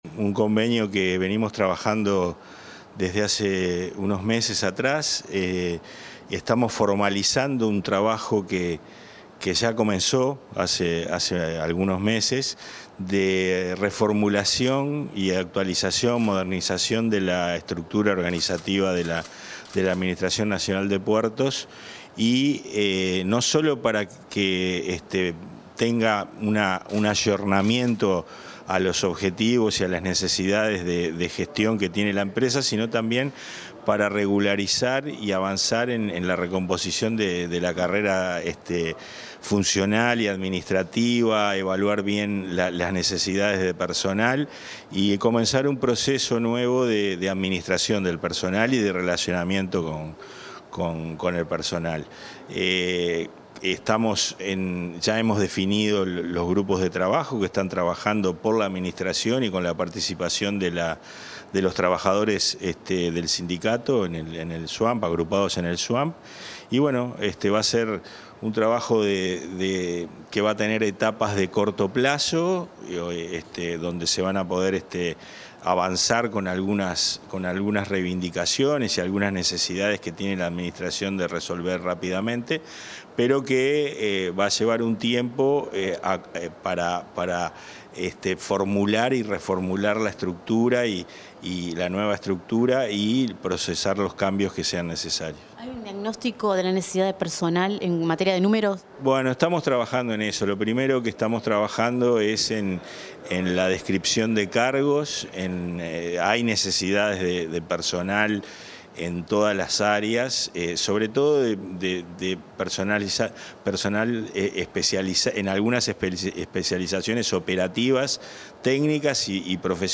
Declaraciones del presidente de la ANP, Pablo Genta
Declaraciones del presidente de la ANP, Pablo Genta 14/08/2025 Compartir Facebook X Copiar enlace WhatsApp LinkedIn En el marco de la firma de un convenio entre la Oficina Nacional de Servicio Civil y la Administración Nacional de Puertos, el titular de esta dependencia, Pablo Genta, realizó declaraciones a la prensa.